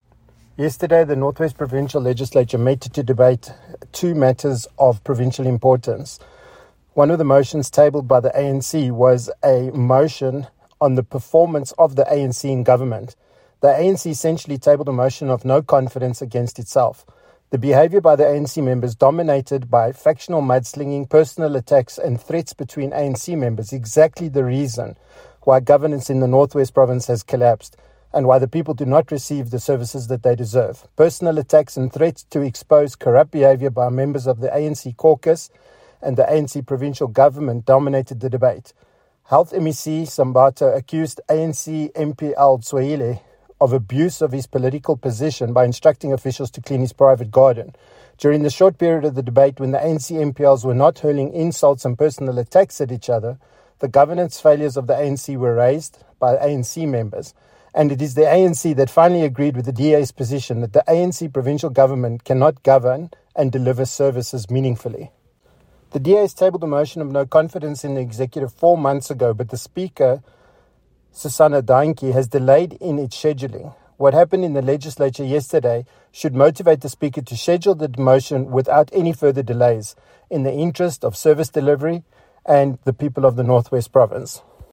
Issued by Gavin Edwards MPL – DA Chief Whip: North West Provincial Legislature
Note to Editors: Please find attached a soundbite in